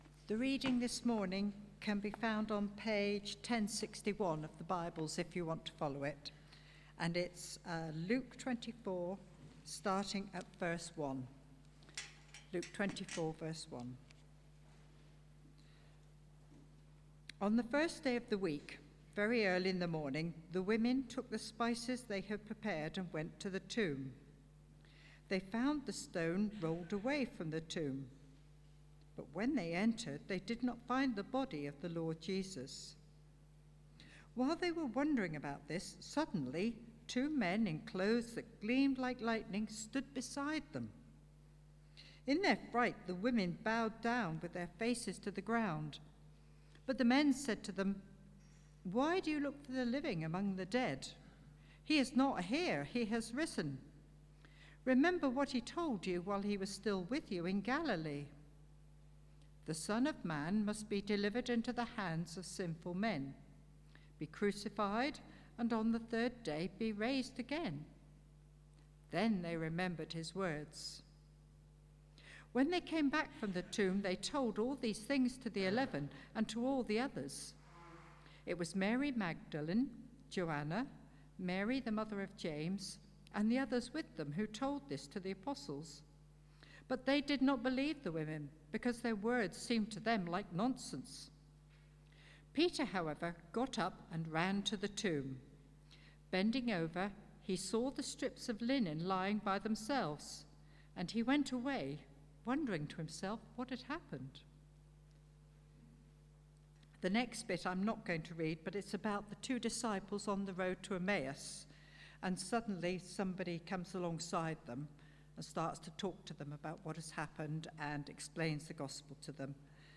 Service Type: Sunday 11:00am